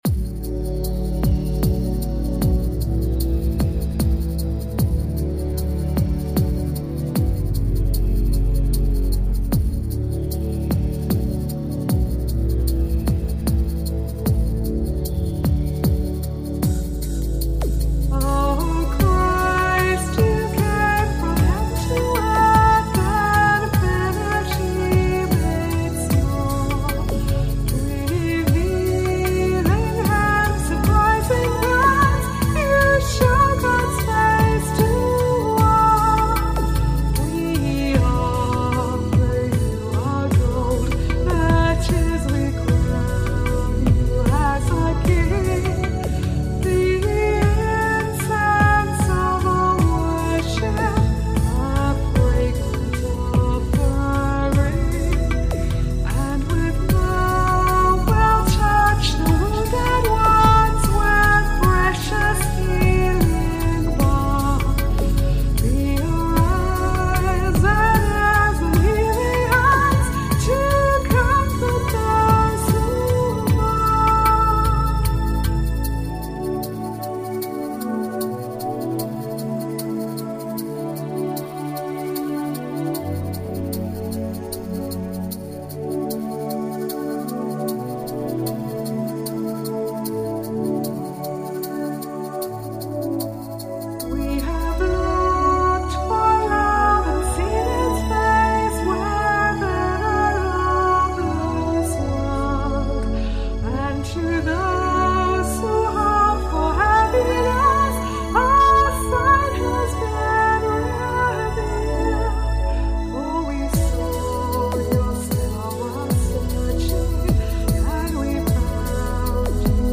The following is an Epiphany Hymn
You can listen to her sing it here.